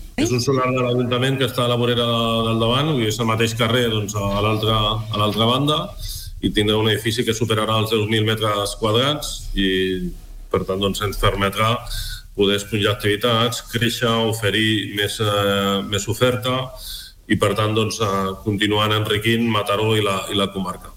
L’alcalde de Mataró, David Bote, ha passat per l’Entrevista del Dia de RCT per parlar del nou projecte d’ampliació del TecnoCampus.